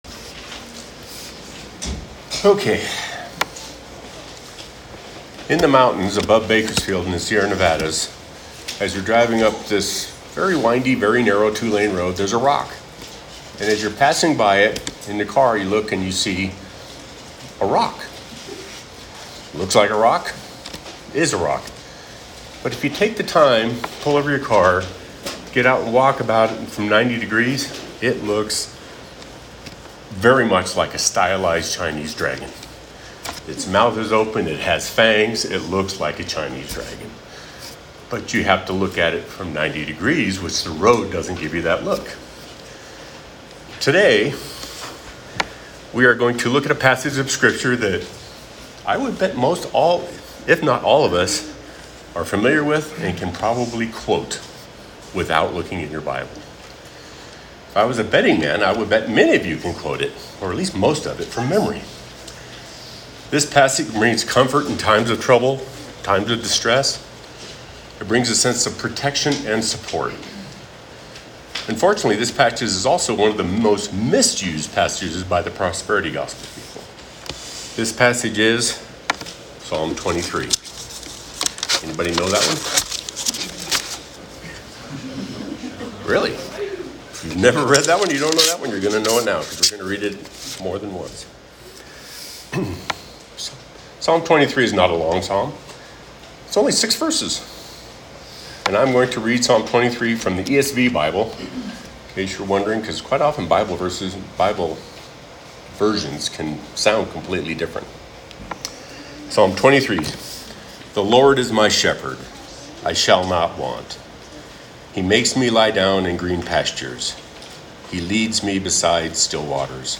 Sermon June 8, 2025